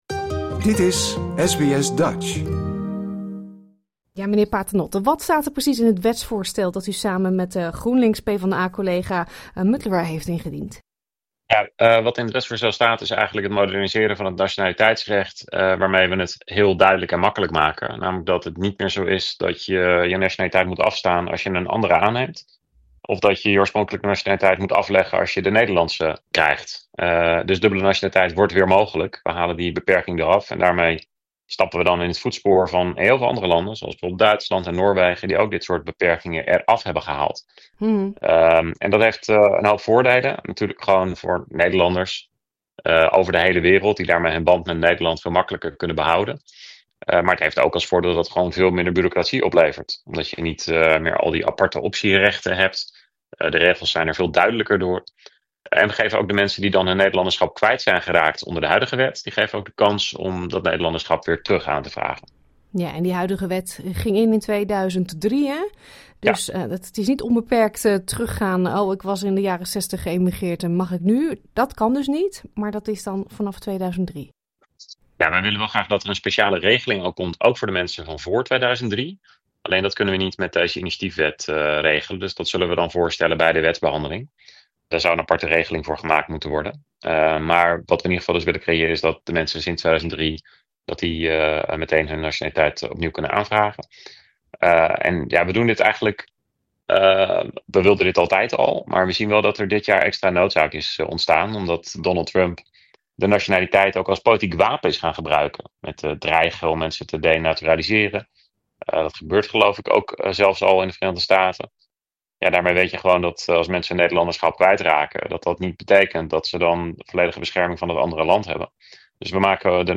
We vragen het aan Tweede Kamerlid Jan Paternotte (D66), één van de initiatiefnemers.